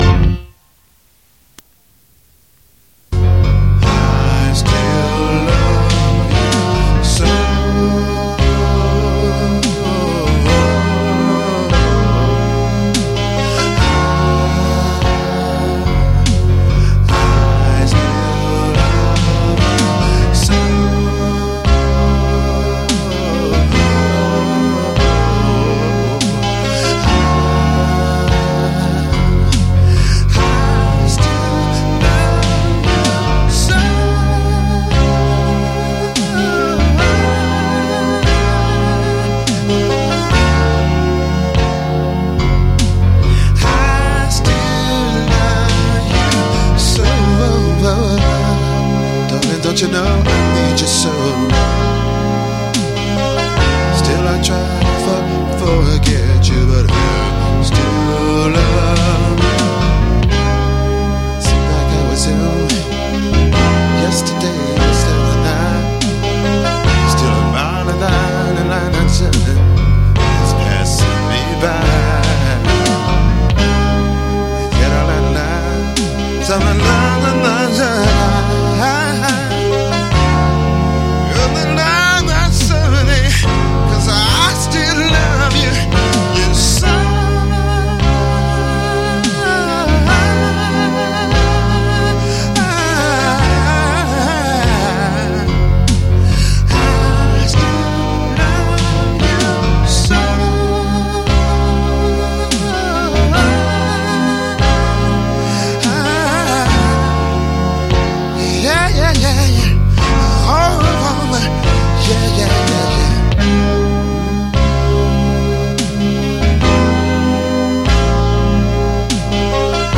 more of my working in the studio on vocal ideas and musical arrangements.